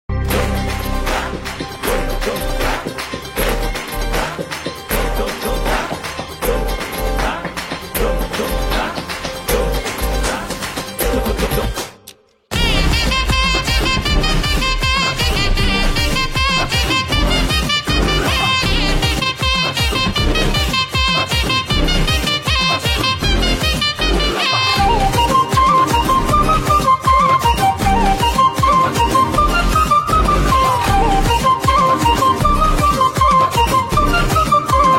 Perfect for weddings, dance, and celebration vibes.